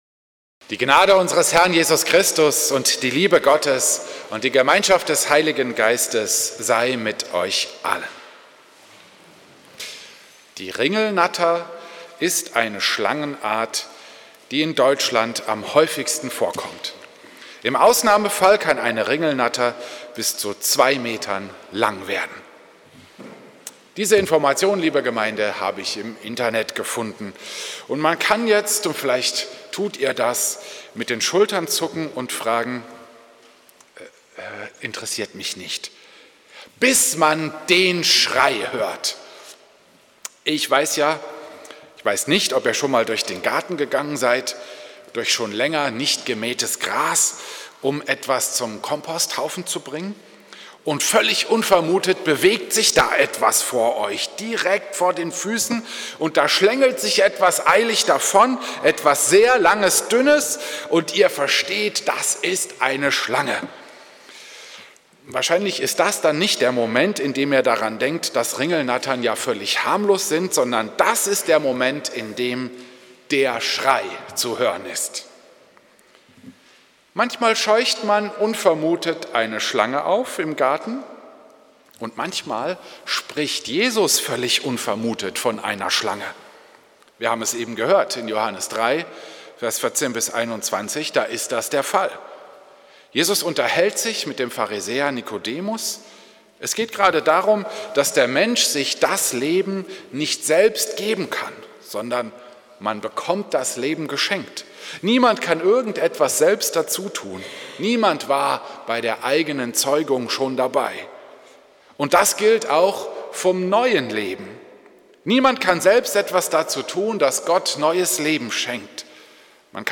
Predigt am Sonntag Reminiszere